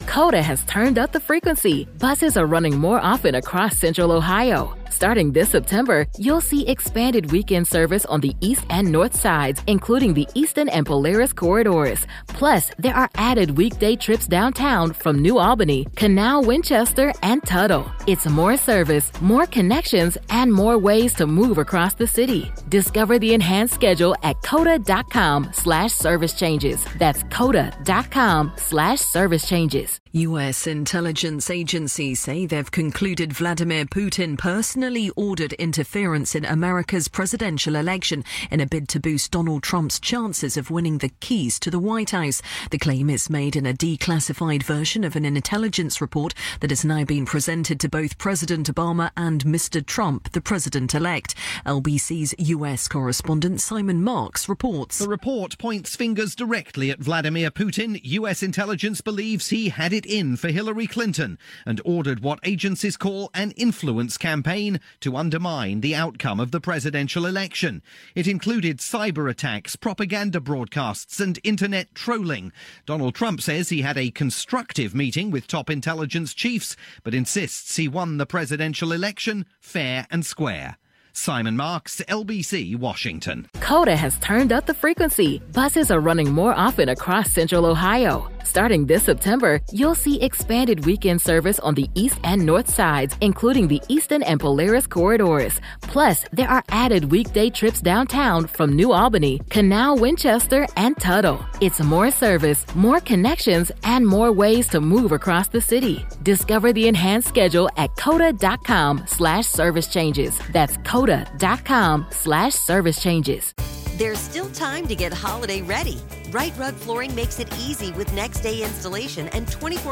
report aired on LBC's hourly news bulletins.